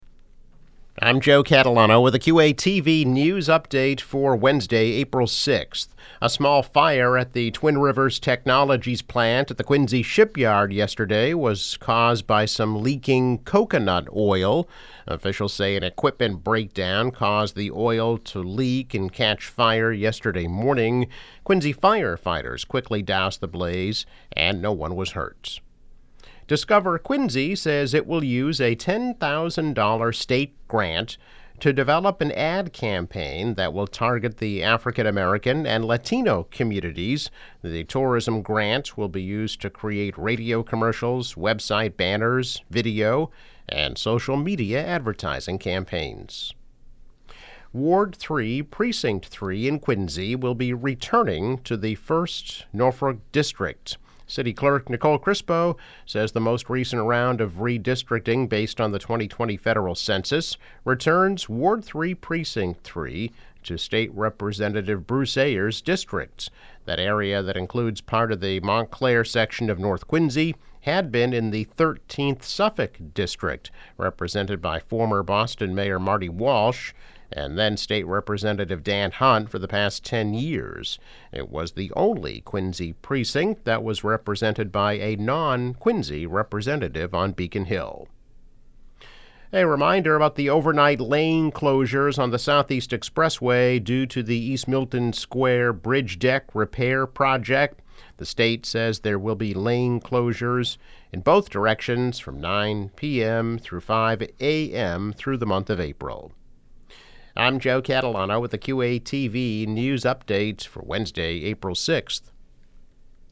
News Update - April 6, 2022